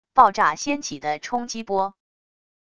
爆炸掀起的冲击波wav音频